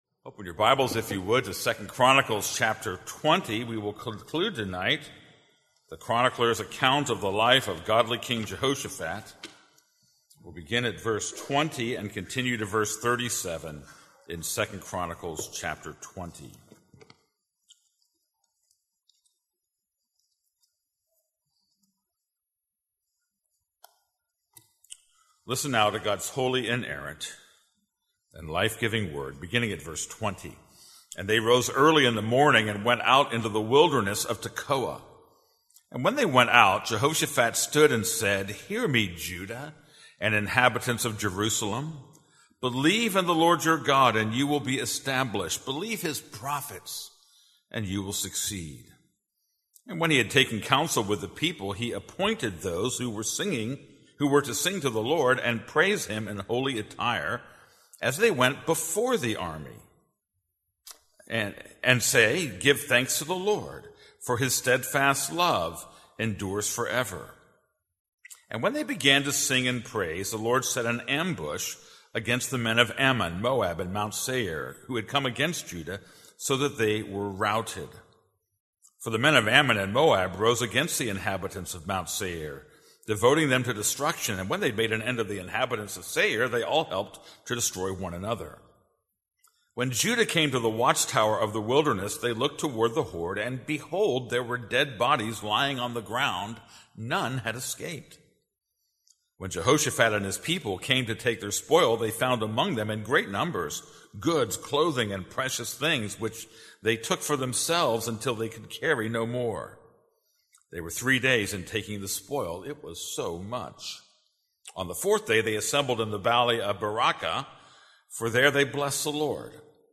This is a sermon on 2 Chronicles 20:20-37.